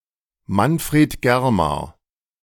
Manfred ("Manni") Germar (German pronunciation: [ˈmanˌfʁeːt ˈɡɛʁ.maːɐ̯]
De-Manfred_Germar.ogg.mp3